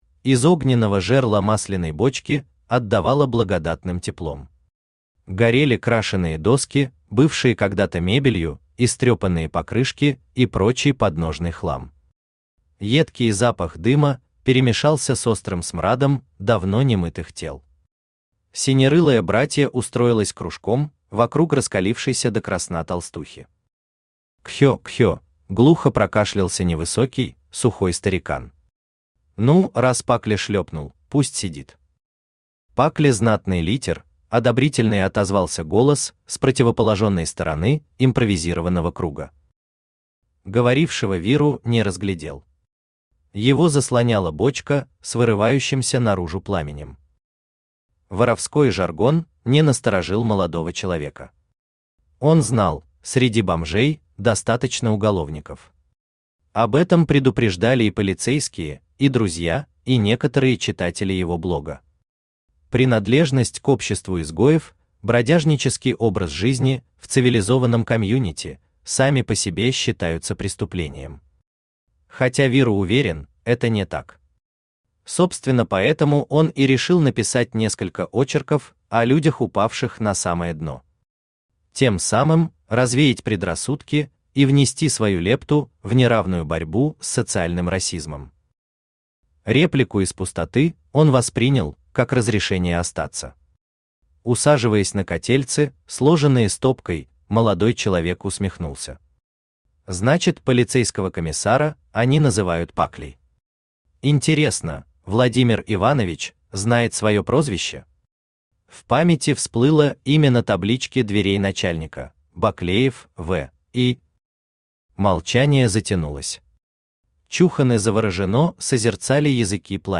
Аудиокнига Выход Вниз. Fast food | Библиотека аудиокниг
Fast food Автор ШаМаШ БраМиН Читает аудиокнигу Авточтец ЛитРес.